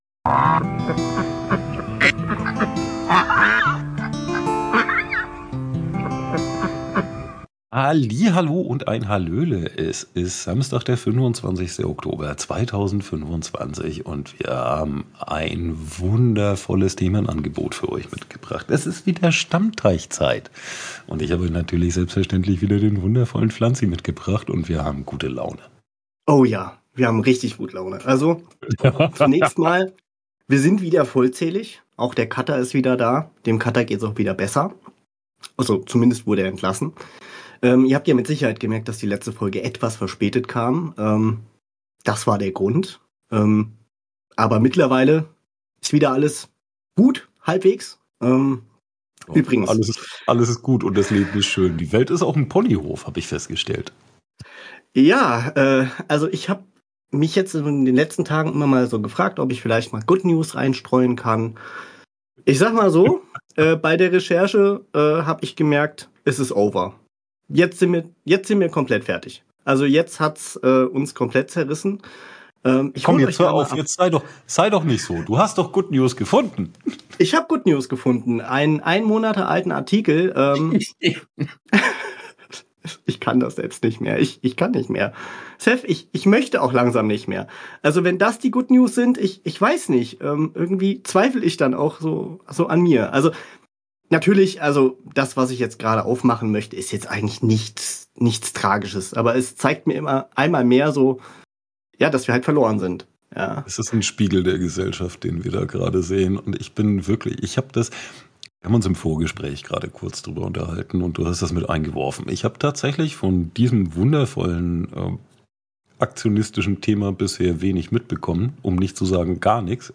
*Lautstärkewarnung!*